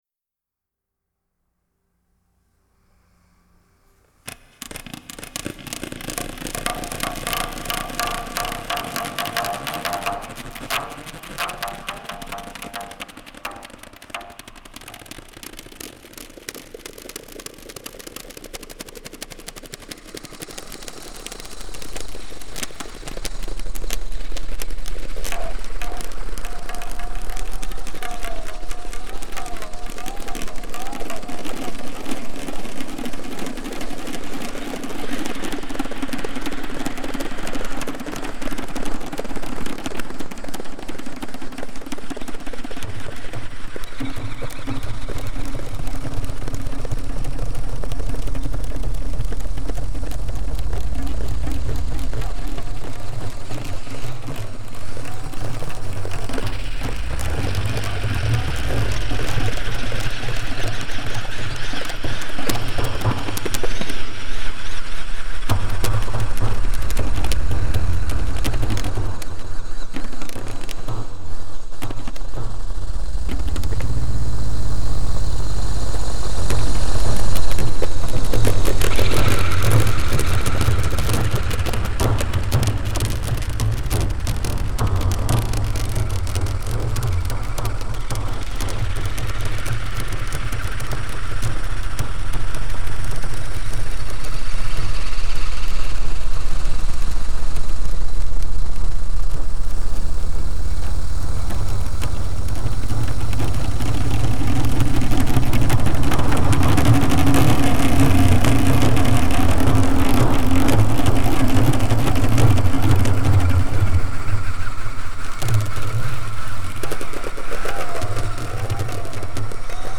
The Intercycle. Birds and Drumming, talkative and miserable nature of natures.
Cosmic_Tabla_SeaCycle_1.mp3